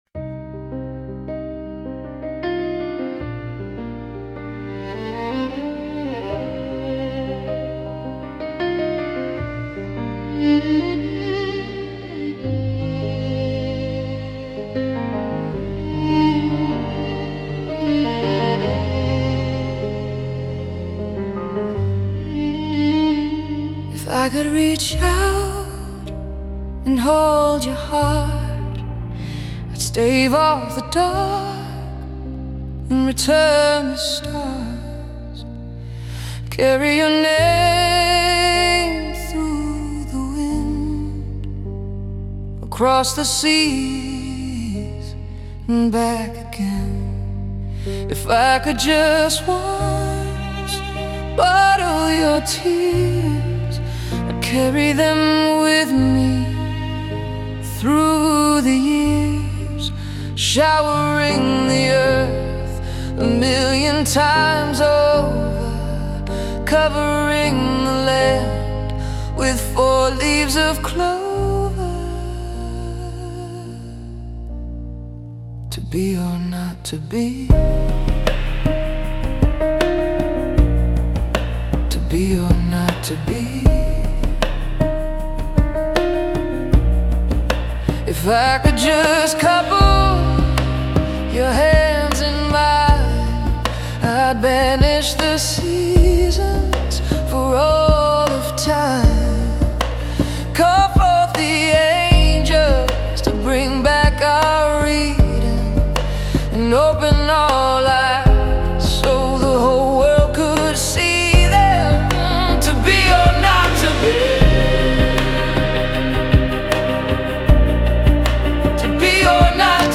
Vocals: Female
Music Genre: pop, contemporary, alternative
Tempo: mid tempo or contemplative
Instruments: violins, piano